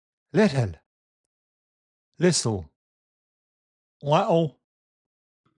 Irish-6mp3.mp3